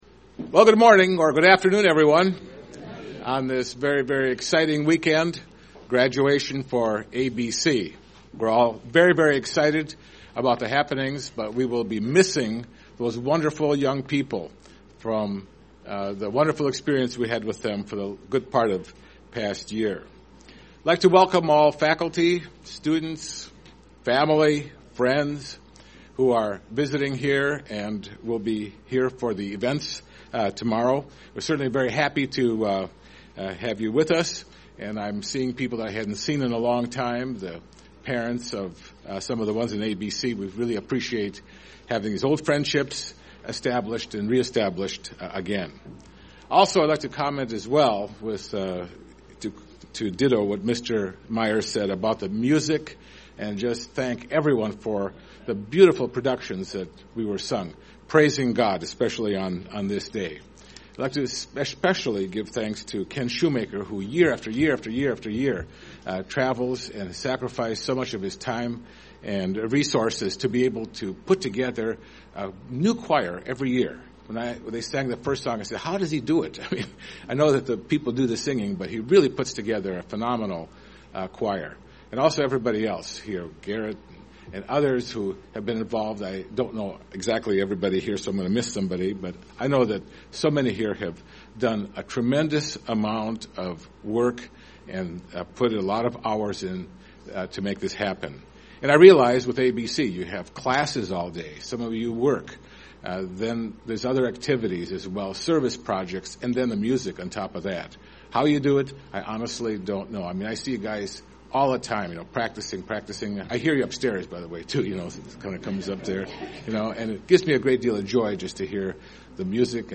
In this baccalaureate message, we learn about the mission of ABC. We're all responsible for setting a good example with our lives.